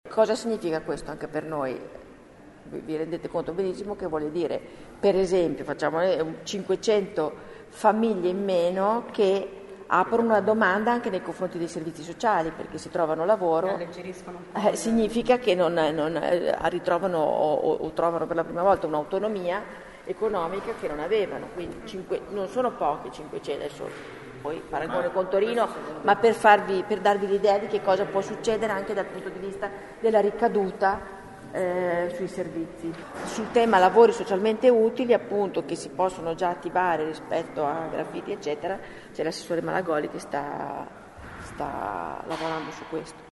A Torino questo provvedimento ha portato 500 inserimenti lavorativi in più nel 2012, spiega l’assessore Frascaroli, fiduciosa in un effetto di alleggerimento per i servizi sociali bolognesi; l’assessore distingue questa misura dai lavori socialmente utili su cui sta lavorando il collega Malagoli.